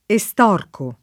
vai all'elenco alfabetico delle voci ingrandisci il carattere 100% rimpicciolisci il carattere stampa invia tramite posta elettronica codividi su Facebook estorcere [ e S t 0 r © ere ] v.; estorco [ e S t 0 rko ], ‑ci — coniug. come torcere